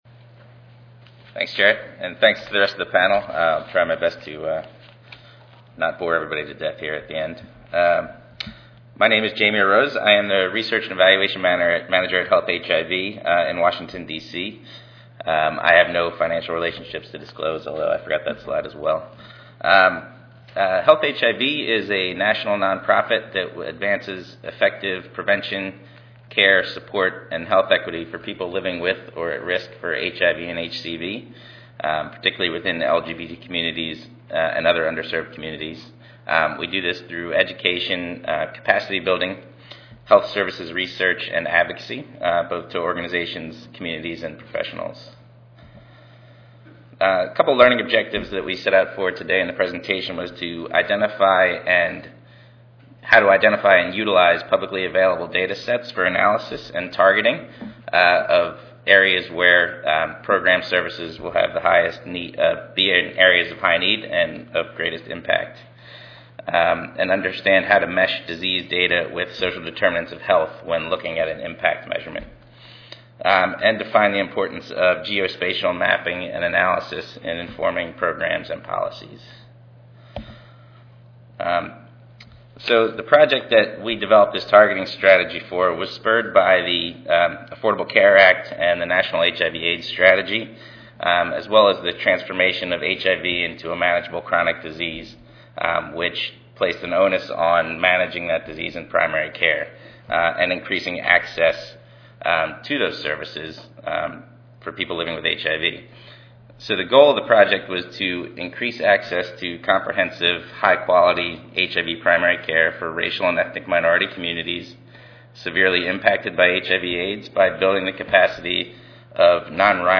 4116.0 Role of place in sexual health and HIV Tuesday, November 5, 2013: 10:30 a.m. - 12:00 p.m. Oral Session Objectives: Describe the importance of place and space in HIV prevention and care.